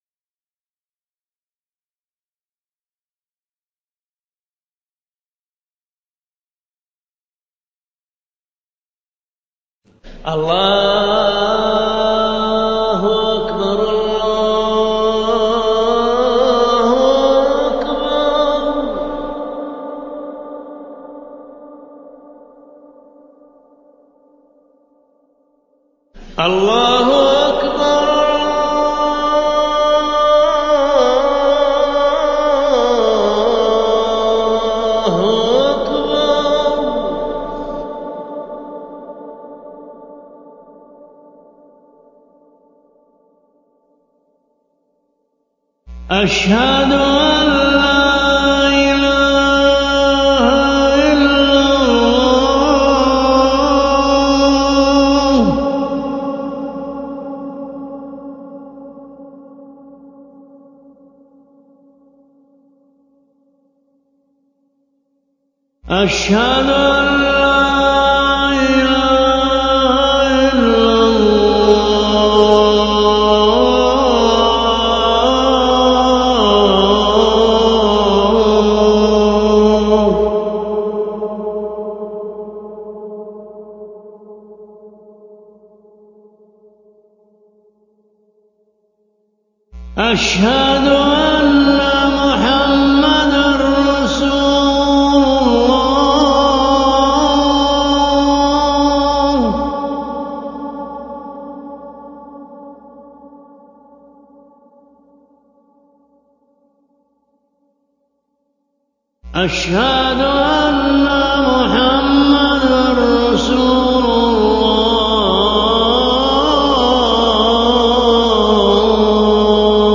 أذان
المكان: المسجد النبوي الشيخ